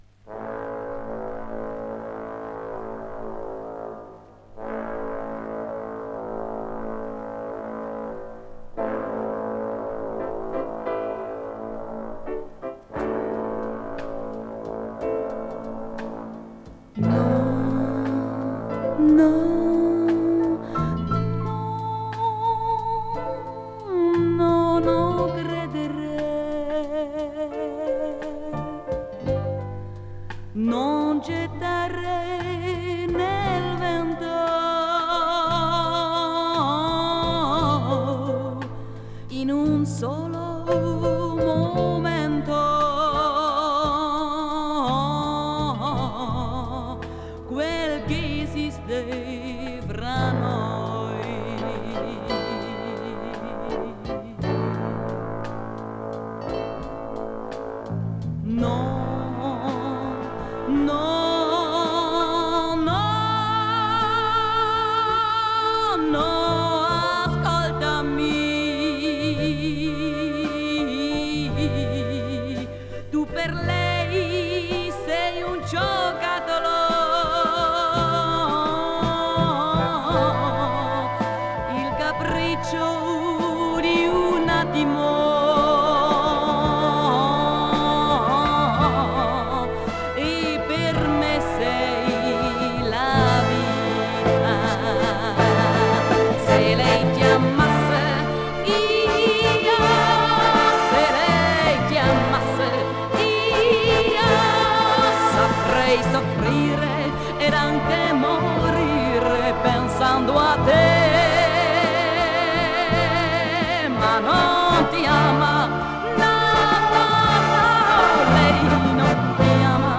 Recorded at The Brno  Radio Studio, 1970.